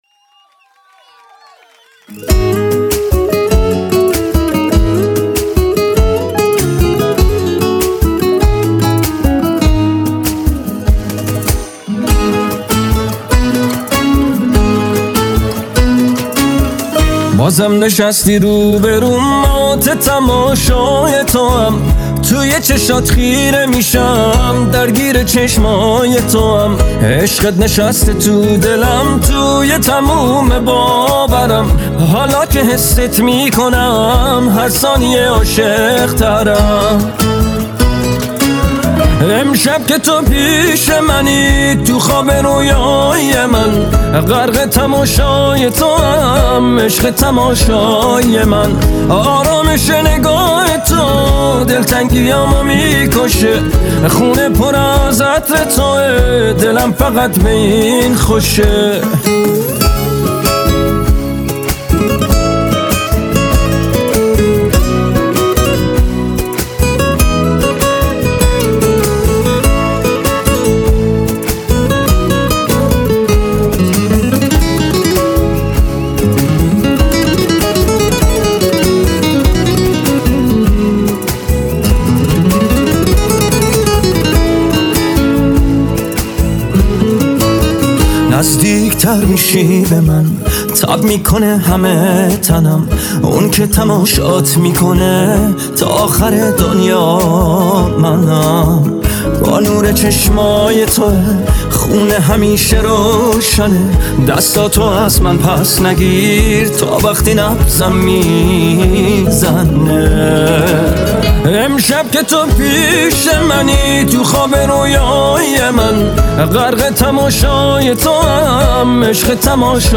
با ترانه‌ای عاشقانه و ملودی دلنشین